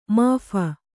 ♪ māpha